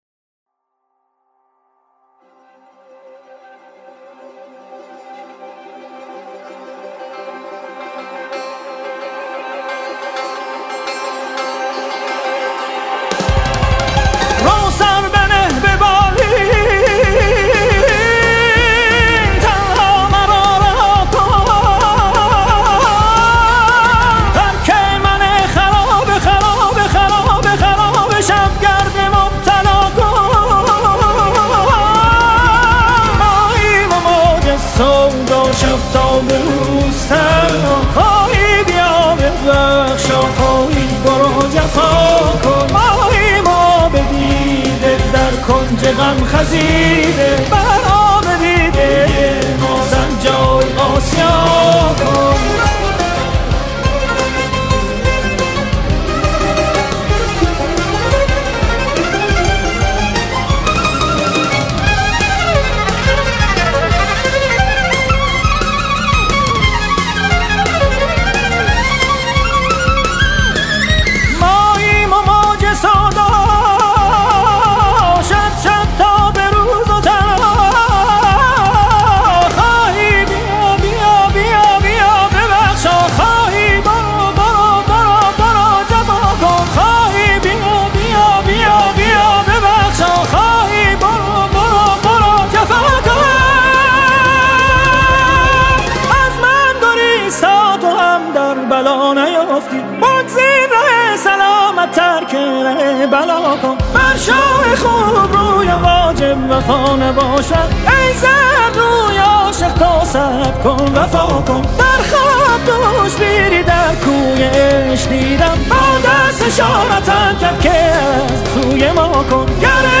cantante persa